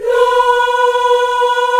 AFROLA B 4-R.wav